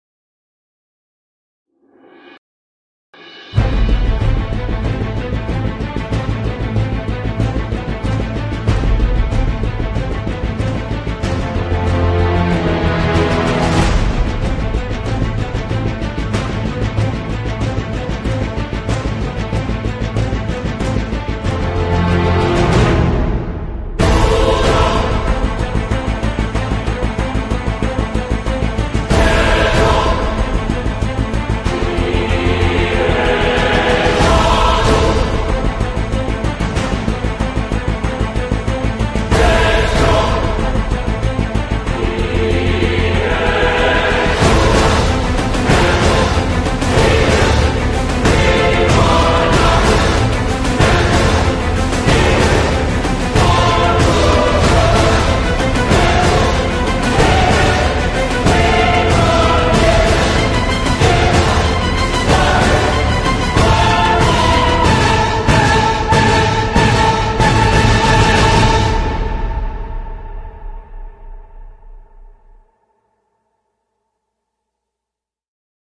МУЗЫКИ С ХОРОМ.